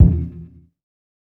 TC3Kick10.wav